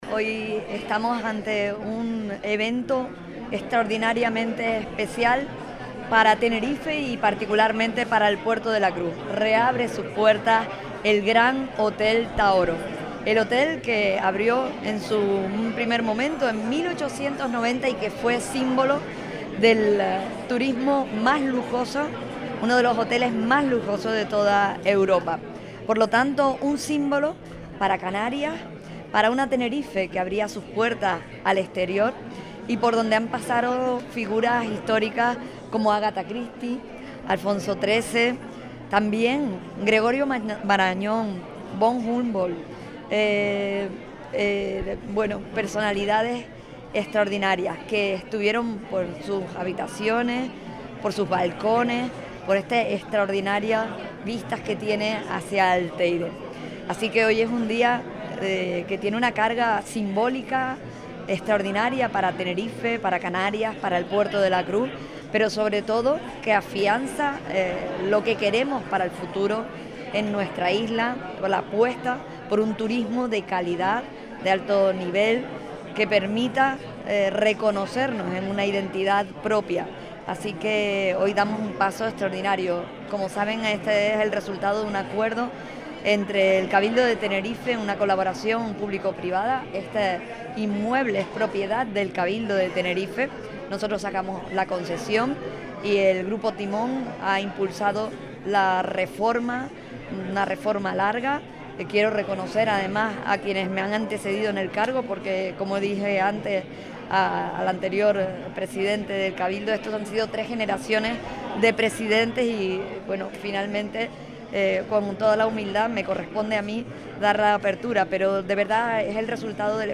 Inauguración Gran Hotel Taoro Puerto de la Cruz - Gente Radio
Declaraciones